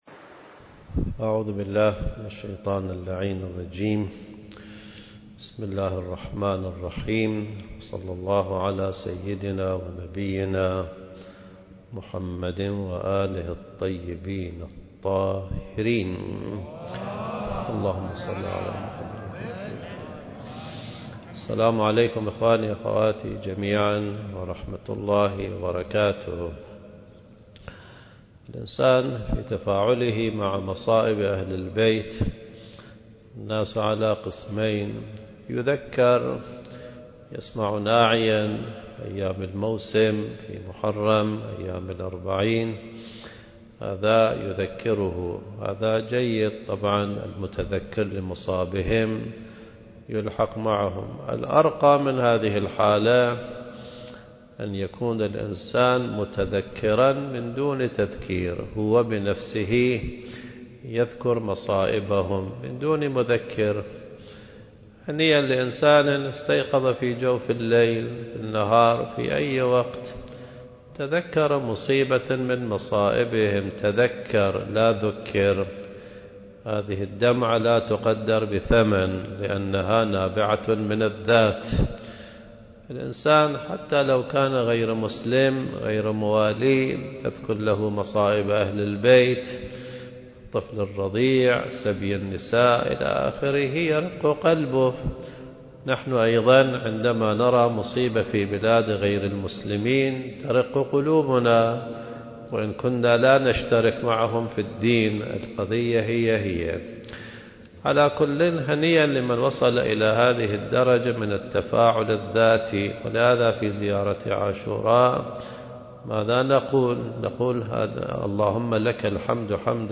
المكان: مسجد الصديقة فاطمة الزهراء (عليها السلام)/ الكويت التاريخ: 2024